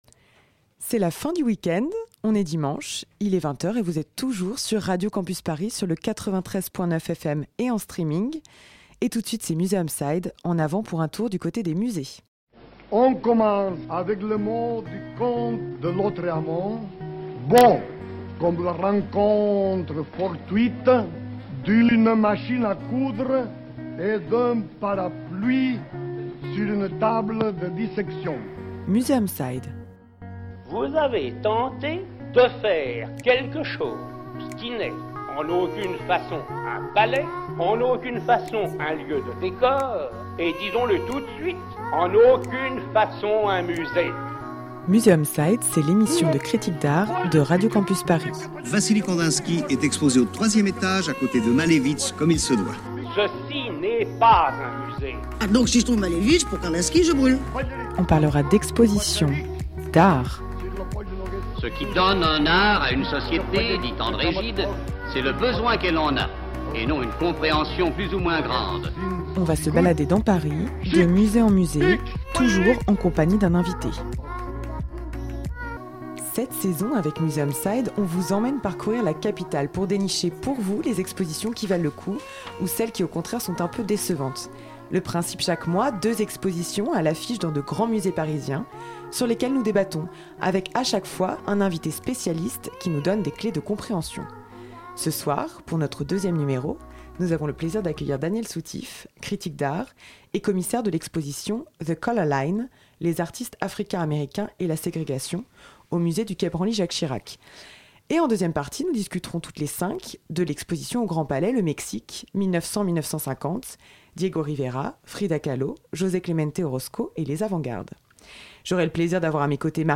Cette saison, avec Museum side, on vous emmène parcourir la capitale pour dénicher pour vous les expositions qui valent le coup d’œil ou celles qui au contraire sont un peu décevantes. Le principe chaque mois : deux expositions à l'affiche dans de grands musées parisiens sur lesquelles nous débattons avec à chaque fois un invité spécialiste qui nous donne des clés de compréhension.